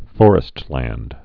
(fôrĭst-lănd, fŏr-)